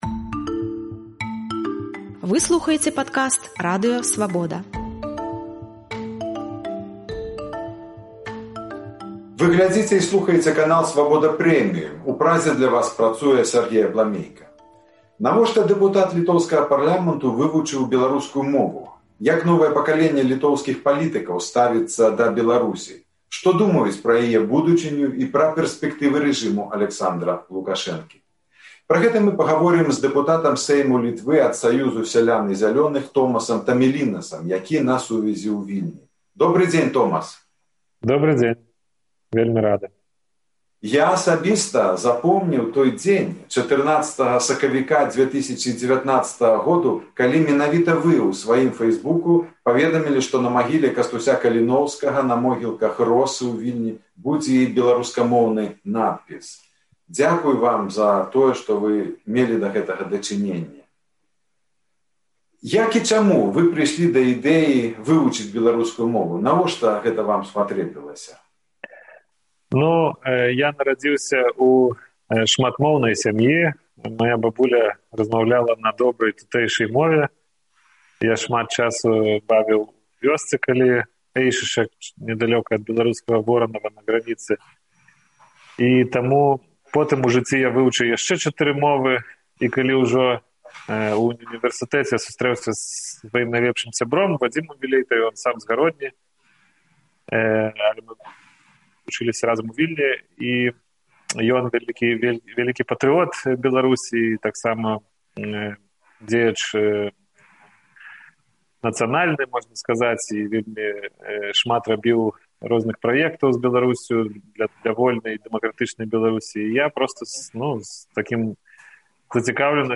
гаворыць з дэпутатам Сэйму Літвы ад Саюзу сялян і зялёных Томасам Тамілінасам.